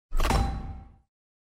ui_interface_26.wav